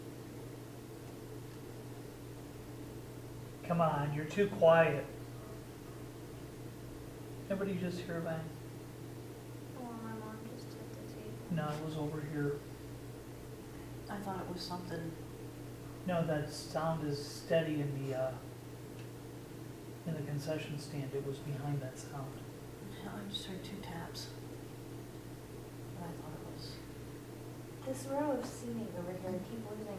Mahanoy City, PA : Old Elks Theater
Electronic Voice Phenomenon (EVP)
Clip 2 (0015 hrs.)—2 additional yells in the background during a conversation between two investigators in the concession lobby area.